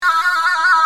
Free MP3 vintage Sequential circuits Pro-600 loops & sound effects 4